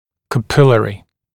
[kə’pɪlərɪ][кэ’пилэри]капилляр, капиллярный